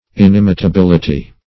inimitability - definition of inimitability - synonyms, pronunciation, spelling from Free Dictionary
Search Result for " inimitability" : The Collaborative International Dictionary of English v.0.48: Inimitability \In*im`i*ta*bil"i*ty\, n. The quality or state of being inimitable; inimitableness.